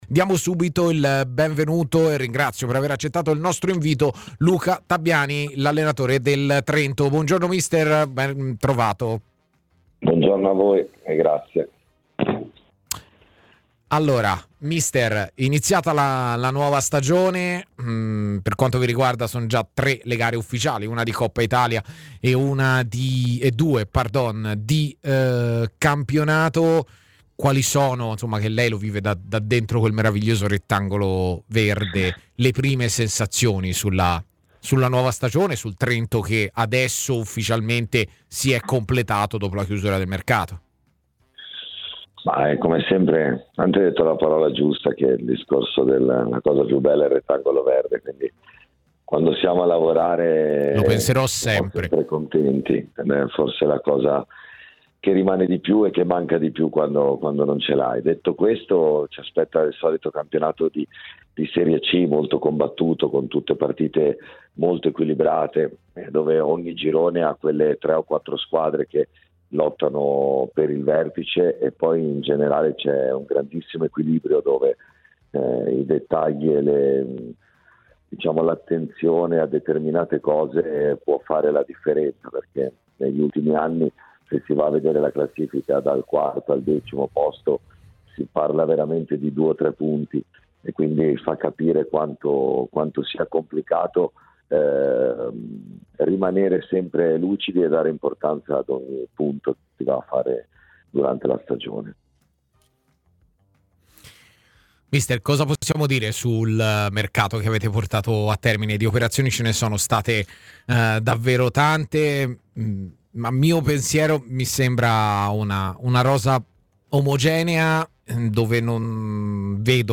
TMW Radio Trento